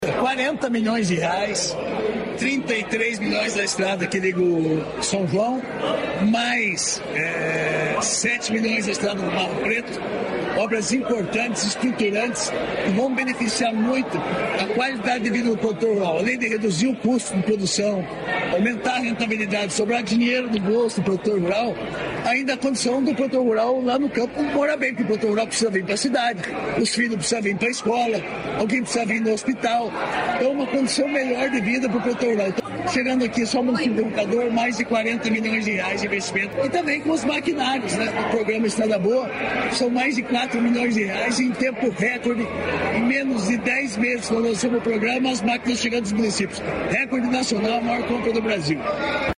Sonora do secretário da Agricultura e Abastecimento, Márcio Nunes, sobre os investimentos em Roncador